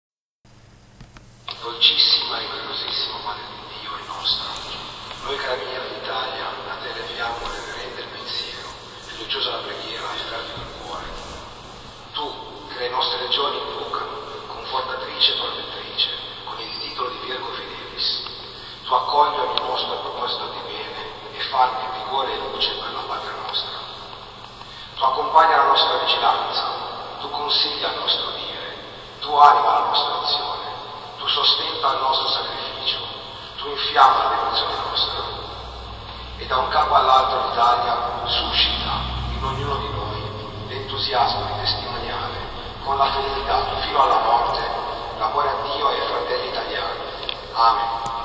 Celebrazioni per la Virgo Fidelis